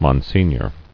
[Mon·si·gnor]